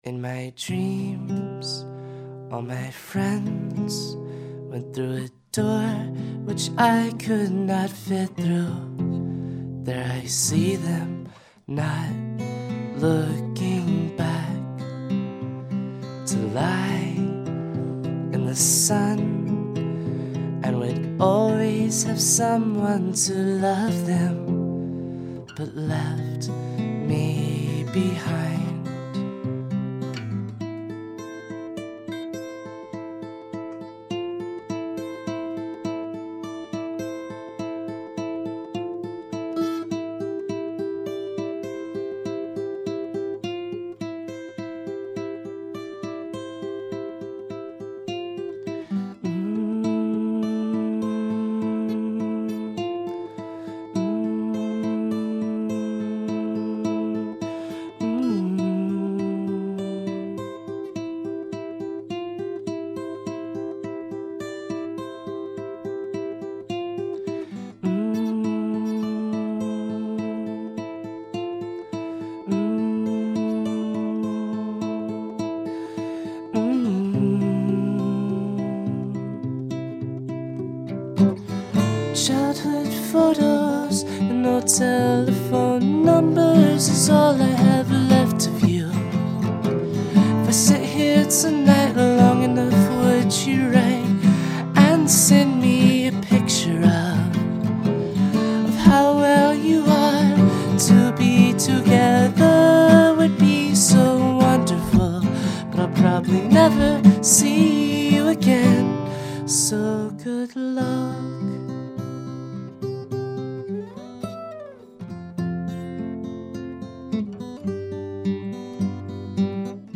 Original folk, international folk and traditional gospel.